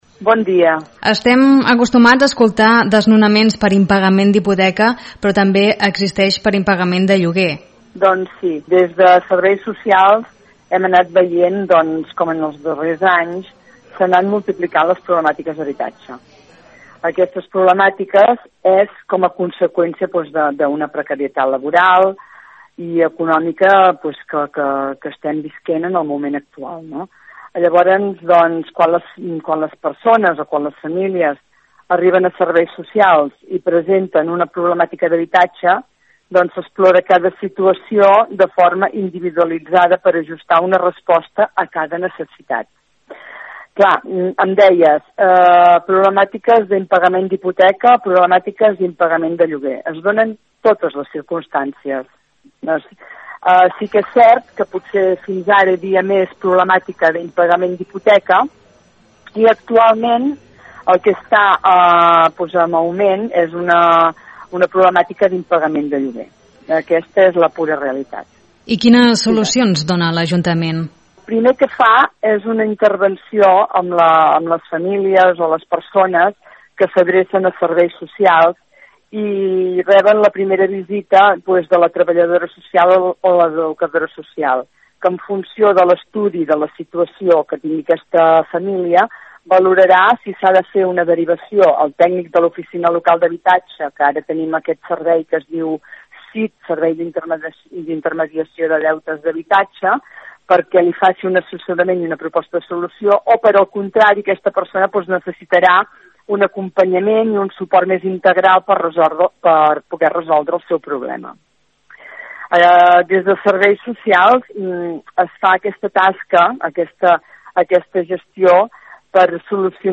A continuació podeu recuperar l’entrevista integra a la regidora de política social Margarita Valls.